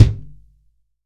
TUBEKICKT3-S.WAV